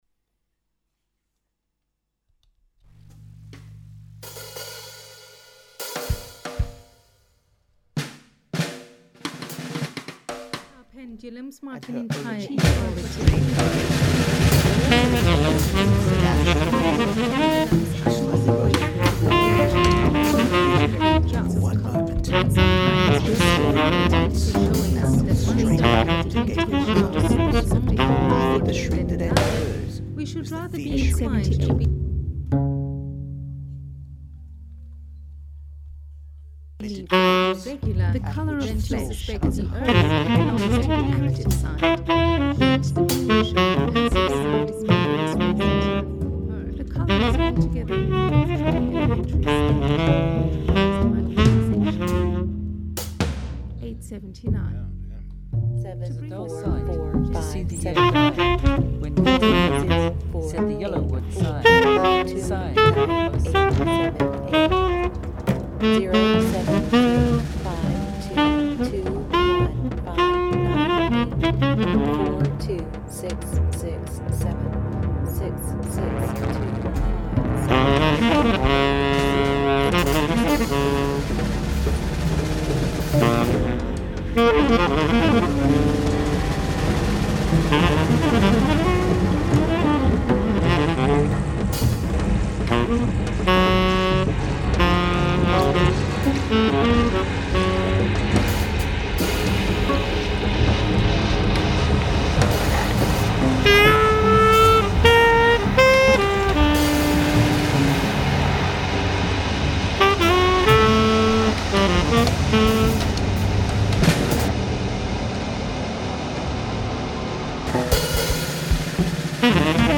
live streamed on Friday 24th of January 2025
Live Music, Saxophone